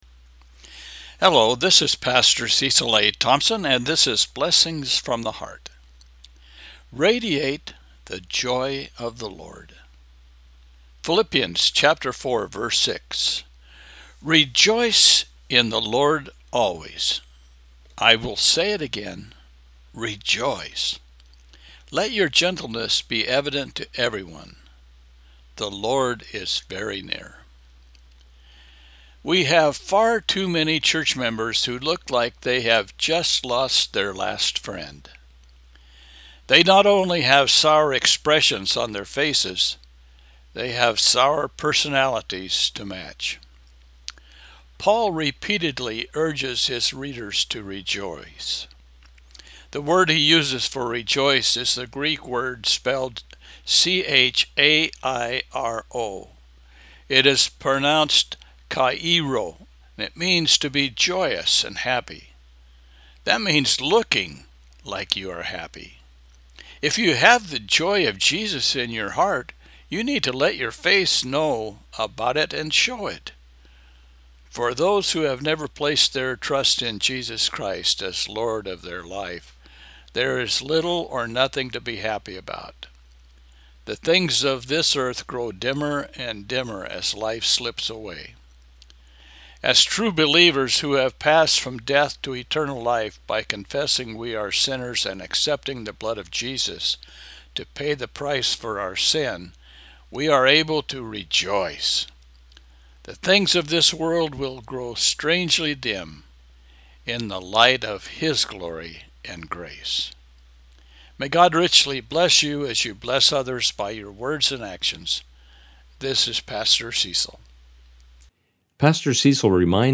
Philippians 4:6 – Devotional